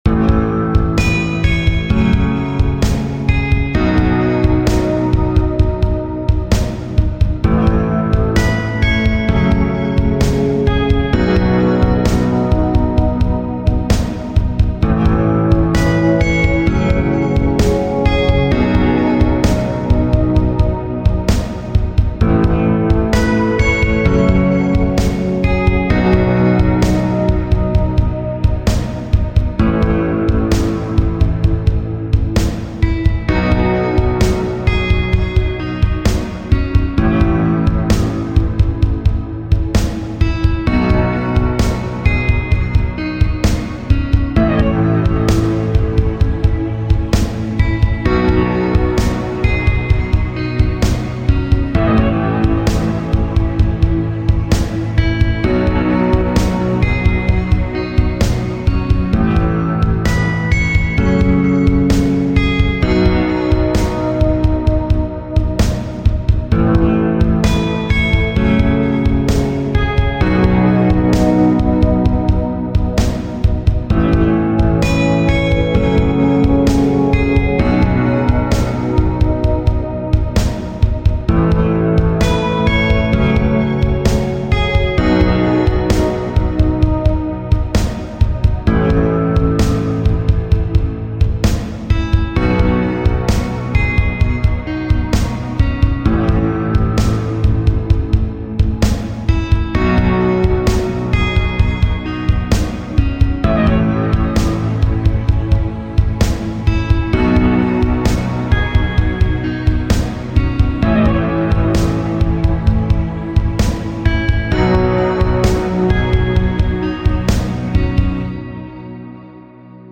Romance – Free Stock Music